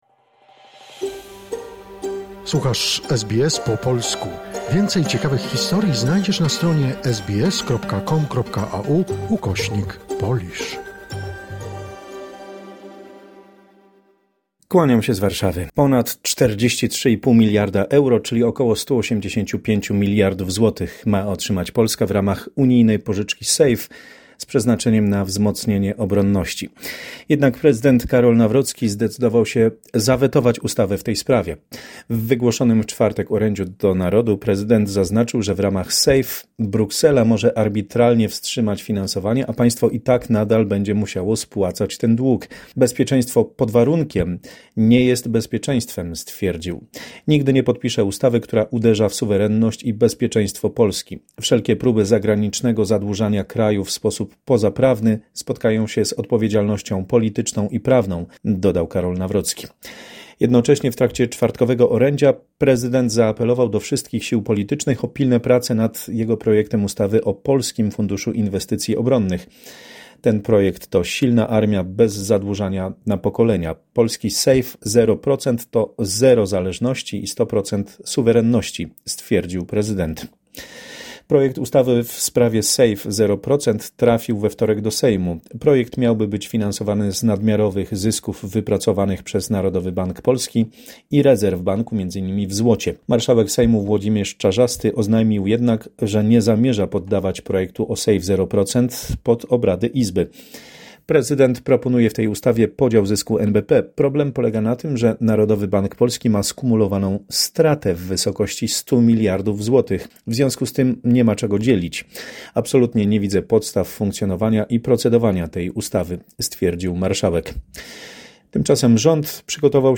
W korespondencji z Polski: Prezydent Karol Nawrocki zawetował program SAFE oraz zablokował nowelizację kodeksu postępowania karnego; w Zielonej Górze, balon uderzył w dach wysokiego budynku, a następnie runął na ziemię dwie ulice dalej...